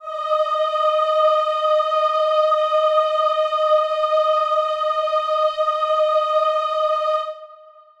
Choir Piano
D#5.wav